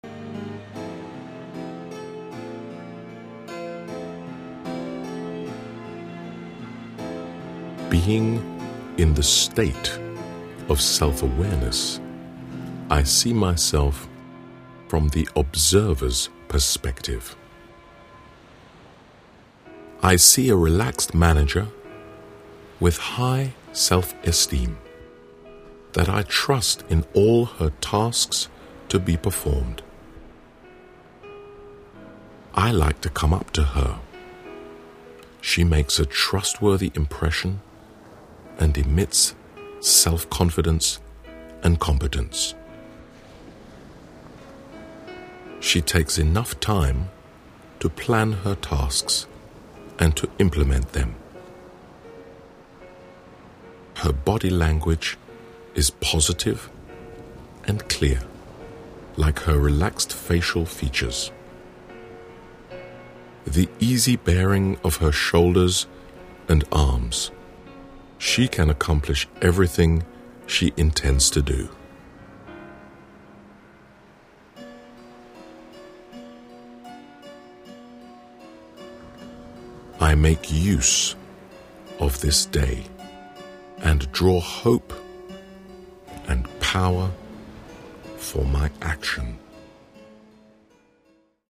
Modules in this audiobook:
The "Power Meditation for Managers" is set to stimulating (neo-)classical music, which particularly induces brain activity, such as joined-up thinking and intuition, and generally animates mental vigor.